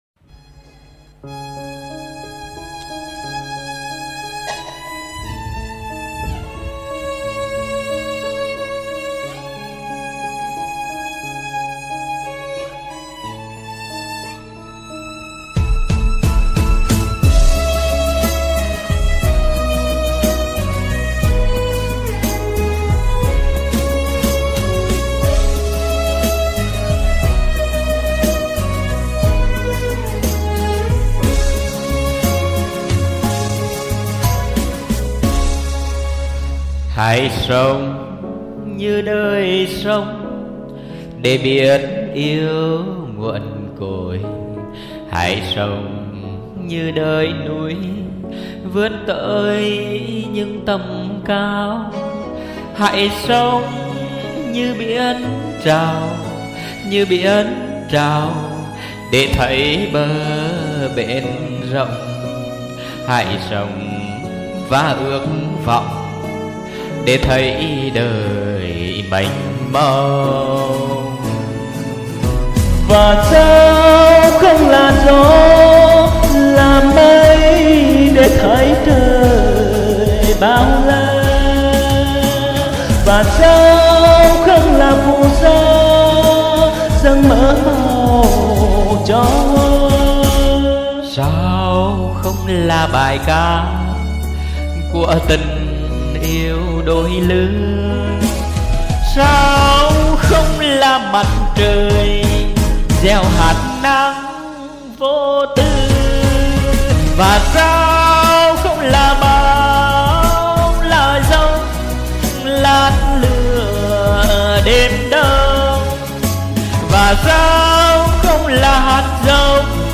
song ca